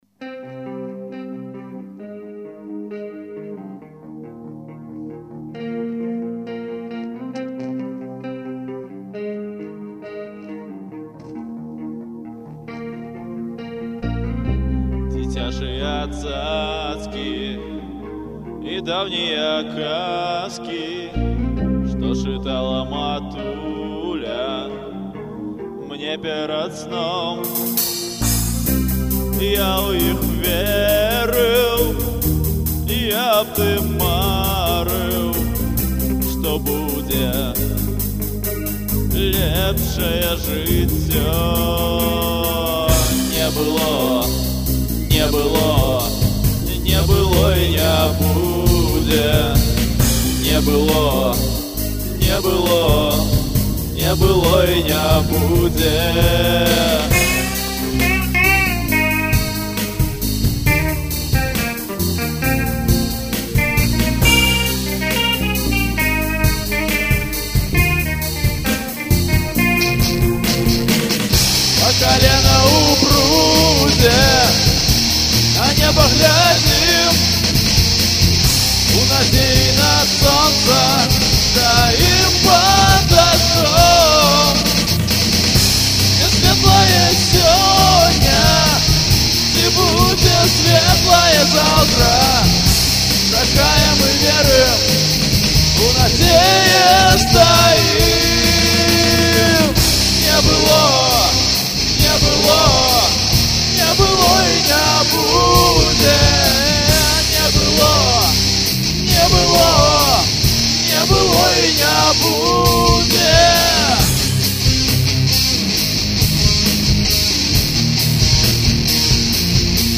Арыгiнальная версiя кампазiцыi
рытм-, бас-, соло-гiтара,